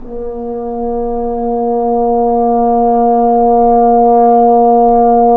Index of /90_sSampleCDs/Roland L-CDX-03 Disk 2/BRS_French Horn/BRS_F.Horn 3 pp